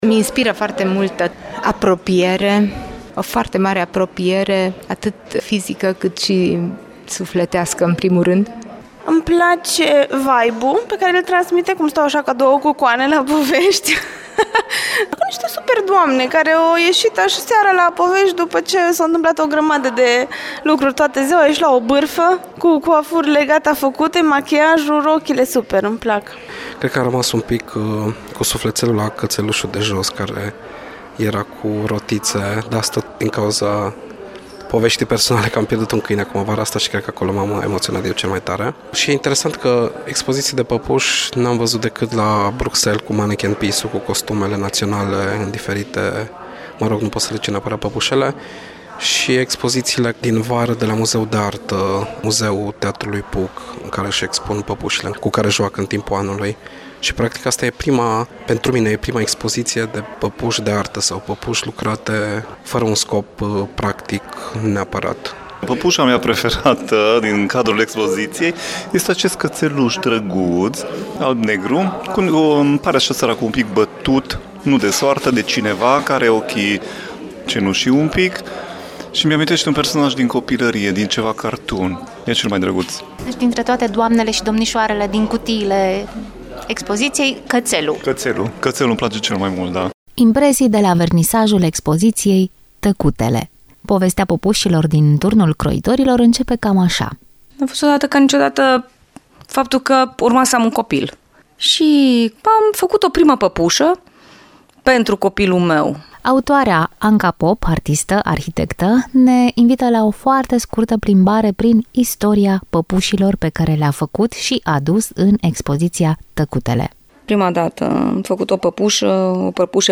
a luat impresii și de la cei prezenți la vernisaj.